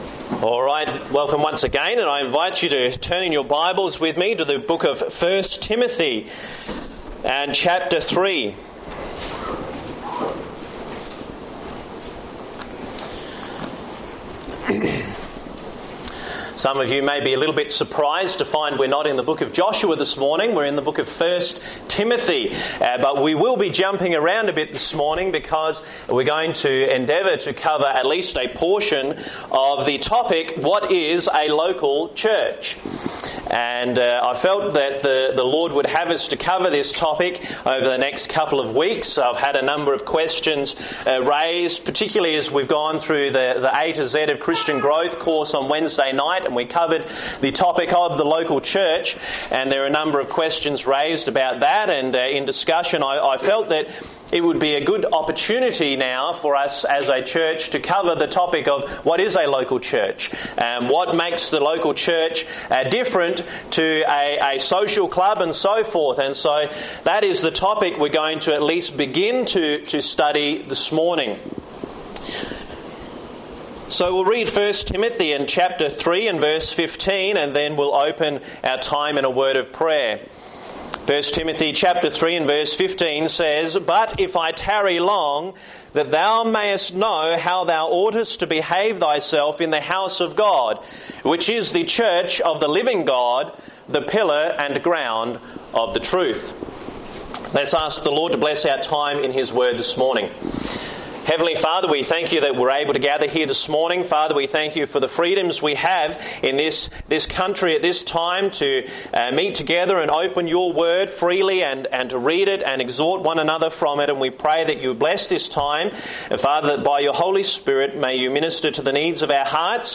This morning, I would like to examine what the Bible has to say about the nature and function of a local church as well as remind us of what our church’s doctrinal statement says about the local church.